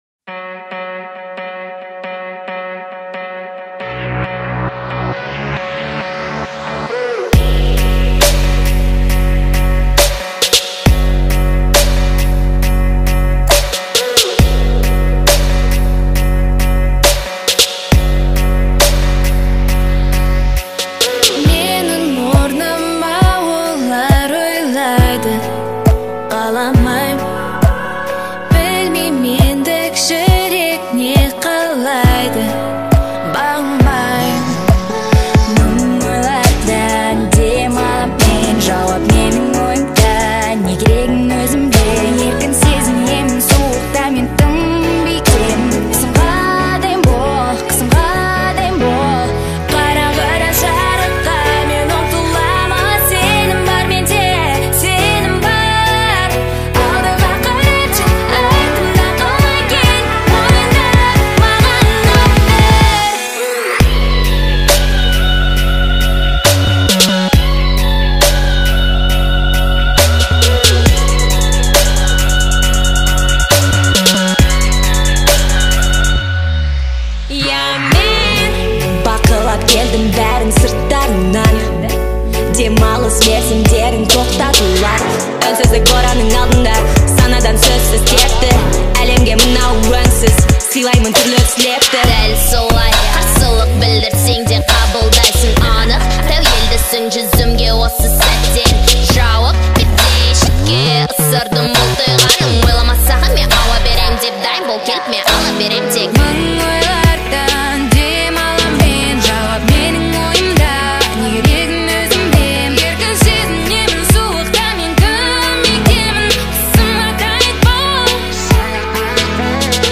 сочетание элементов электронной музыки и хип-хопа